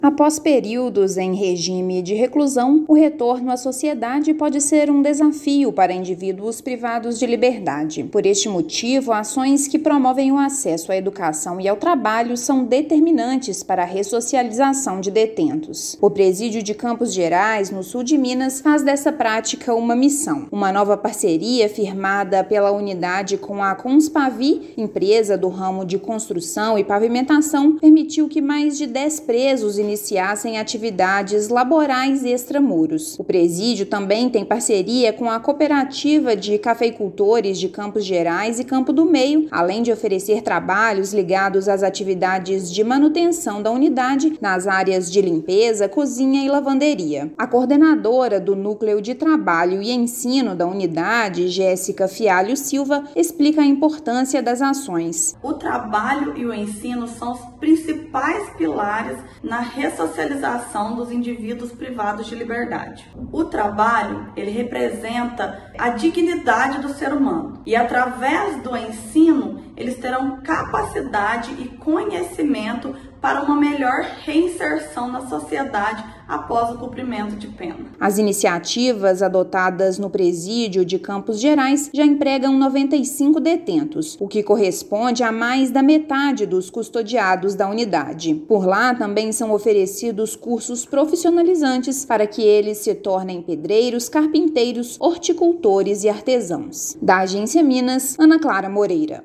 Iniciativa realizada no Presídio de Campos Gerais abre possibilidades de um novo futuro após cumprimento da pena. Ouça matéria de rádio.